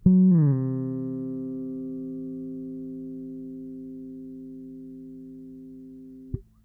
bass6.wav